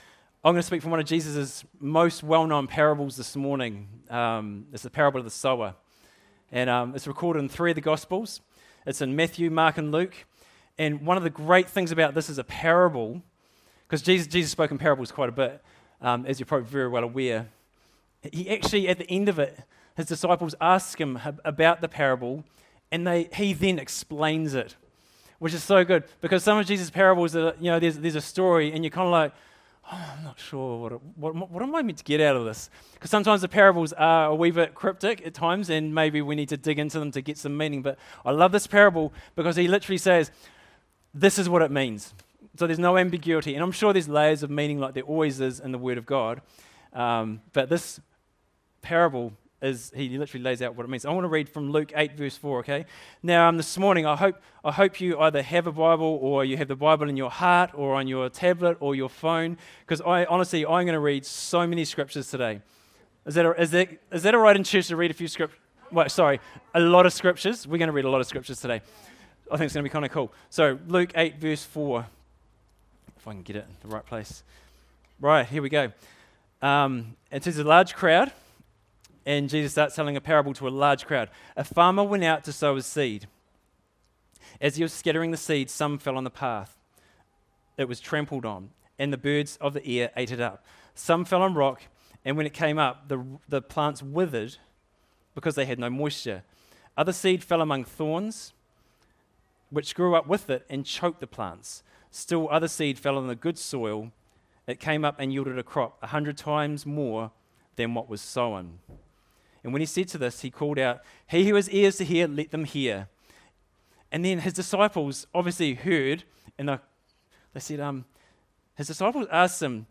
Sermons | Living Waters Christian Centre